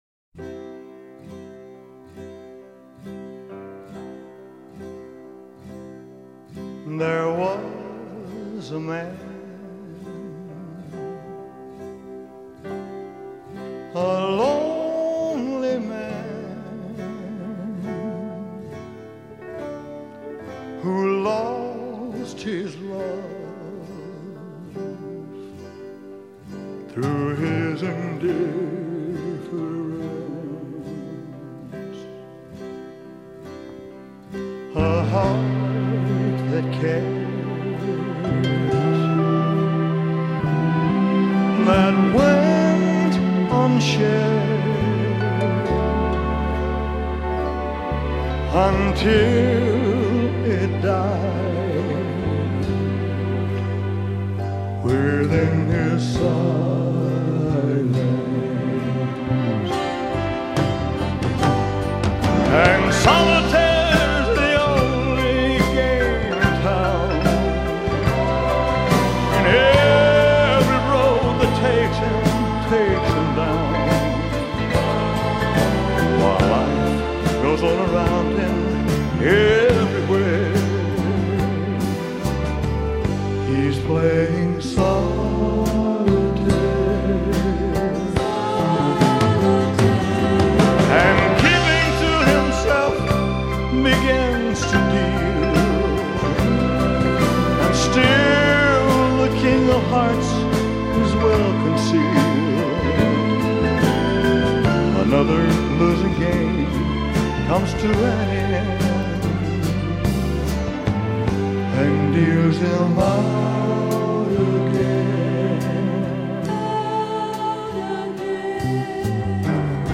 Un classique du genre chanté par une voix unique !